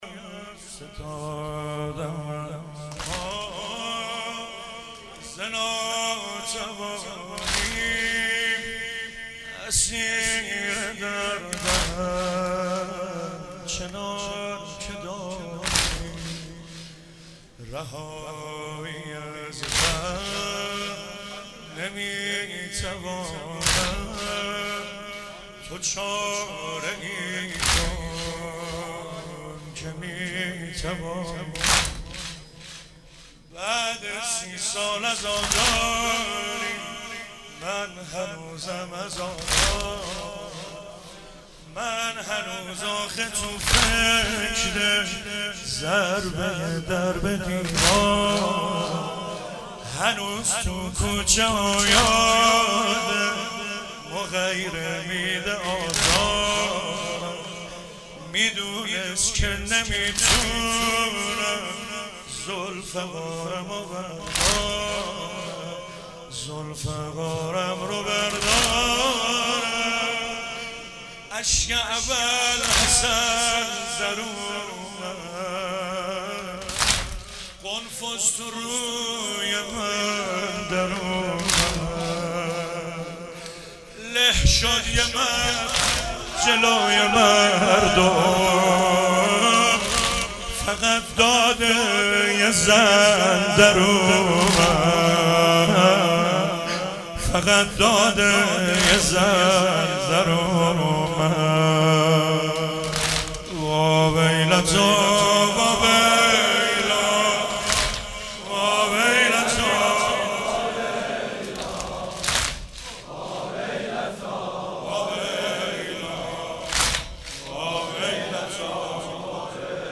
شب 21 رمضان 97 - واحد - فتادم از پا ز ناتوانی اسیر دردم چنان که دانی
ماه رمضان